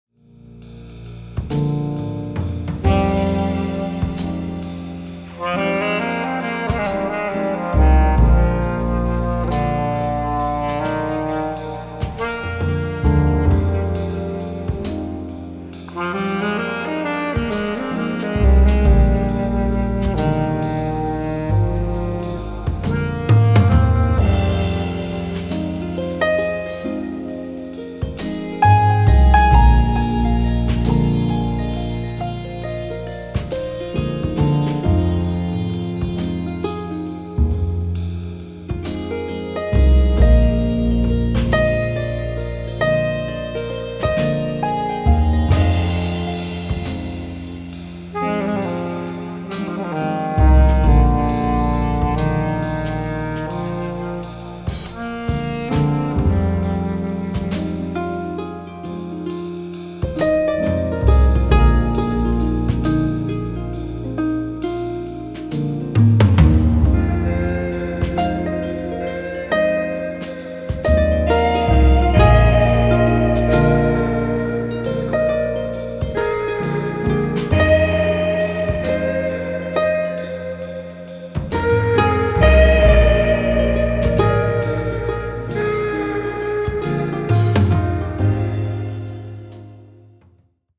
Jazz Adventure